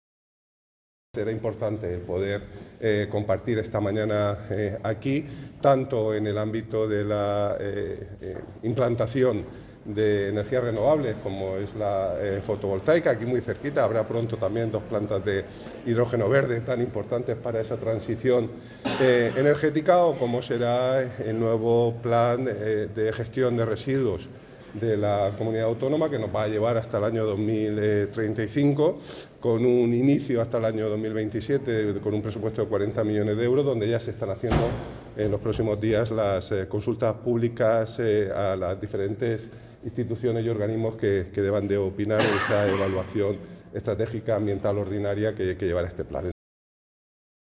Declaraciones del consejero de Medio Ambiente, Universidades, Investigación y Mar Menor, Juan María Vázquez, sobre el Plan Recircula